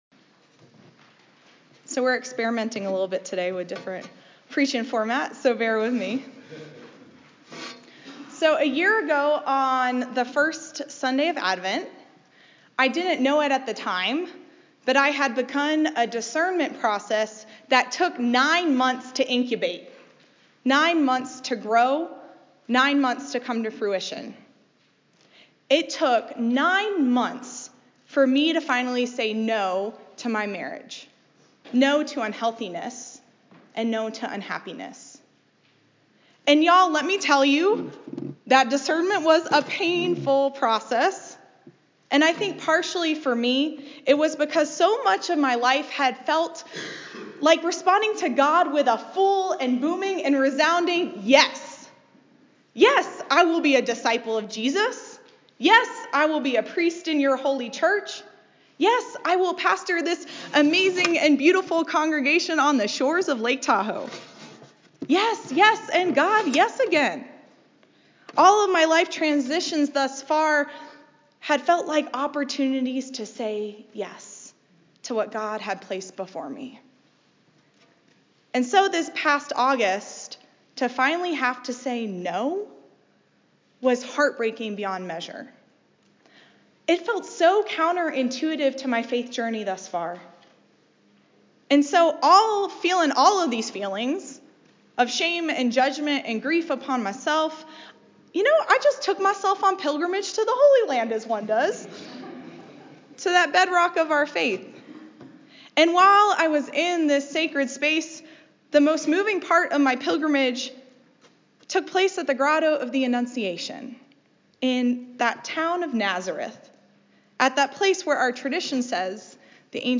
A Sermon for the 2nd Sunday of Advent, Year A